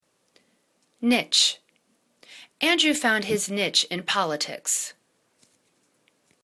niche      /nich/    n